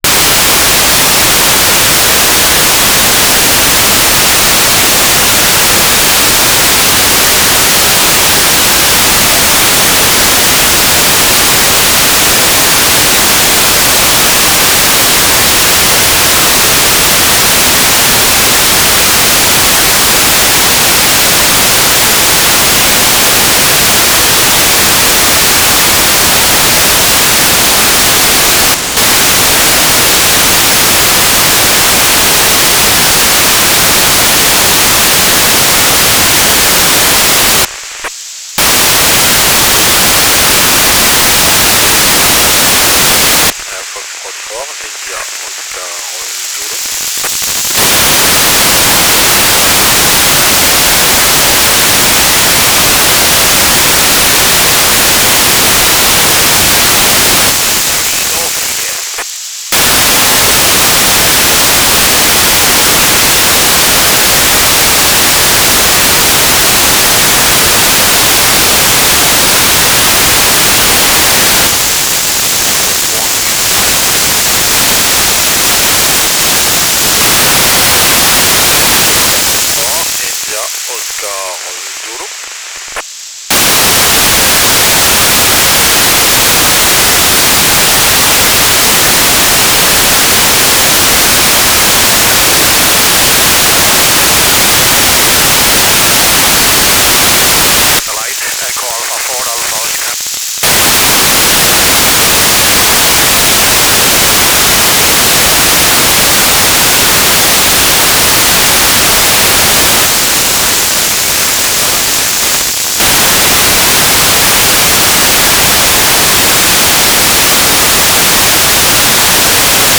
"transmitter_description": "Mode V/U FM - Voice Repeater CTCSS 67.0 Hz",
"transmitter_mode": "FM",